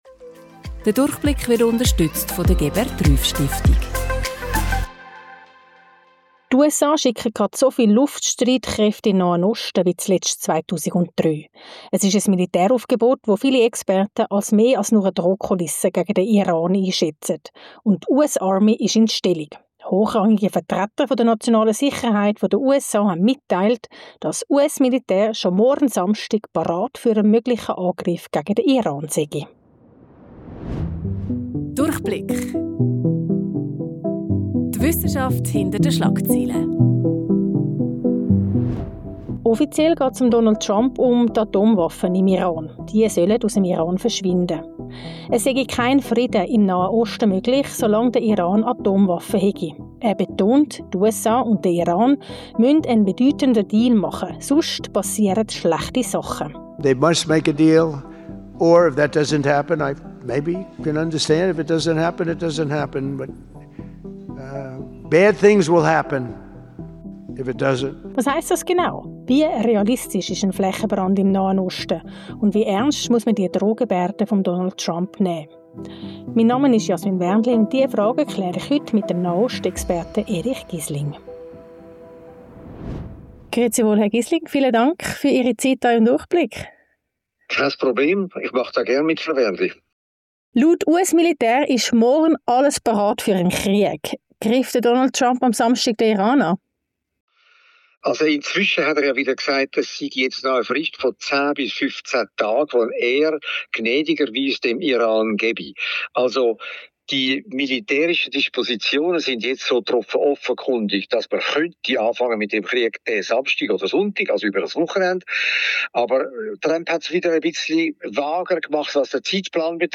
Die Analyse mit Nahost-Experte Erich Gysling.